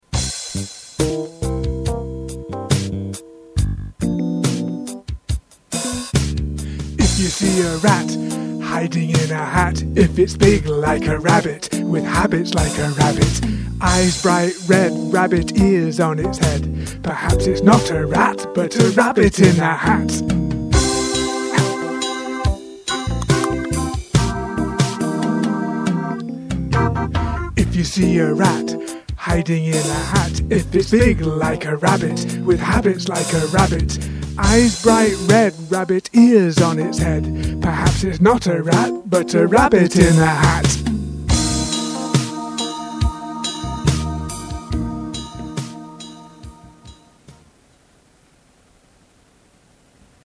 Connected speech, /h/ /r/ minimal pairs
Chanting
The bolded syllables in the rap show where the beat falls.
A very original and funny pronunciation drill.